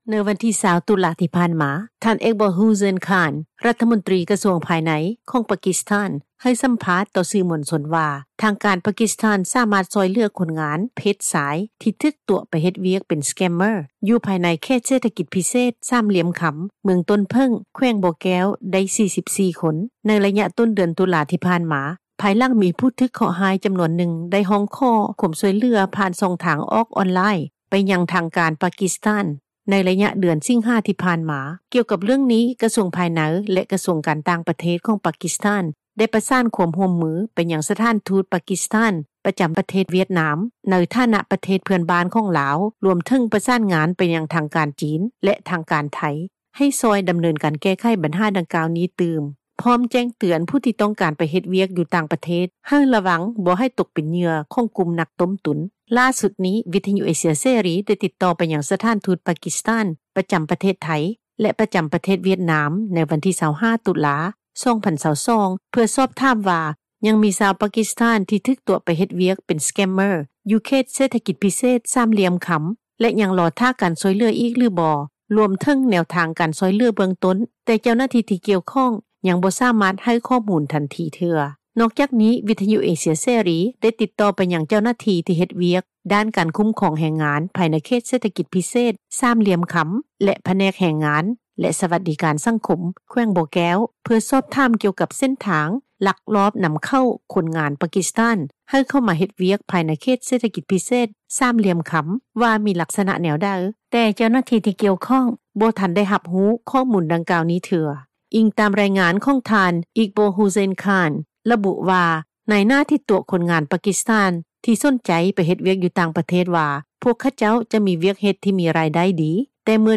ດັ່ງ ຄົນງານລາວ ທີ່ໄດ້ຮັບການຊ່ອຍເຫລືອ ພາຍຫລັງຖືກຕົວະ ໃຫ້ເຮັດວຽກເປັນສແກມເມີ້ຣ໌ ຢູ່ກາສິໂນ ຄິງສ໌ໂຣມັນ ທ່ານນຶ່ງ ກ່າວວ່າ:
ດັ່ງພໍ່ຄ້າຮັບຫິ້ວເຄື່ອງ ຢູ່ເຂດເສຖກິຈພິເສດ ສາມຫລ່ຽມຄຳ ທ່ານນຶ່ງກ່າວວ່າ:
ດັ່ງ ຜູ້ທີ່ຮູ້ກ່ຽວກັບເຣຶ່ອງນີ້ ຢູ່ເມືອງຕົ້ນເຜິ້ງ ແຂວງບໍ່ແກ້ວ ນາງນຶ່ງກ່າວວ່າ:
ດັ່ງ ຜູ້ທີ່ມີປະສົບການ ເຮັດວຽກຕອບແຊັດ ທ່ານນຶ່ງ ກ່າວວ່າ: